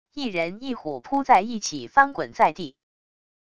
一人一虎扑在一起翻滚在地wav音频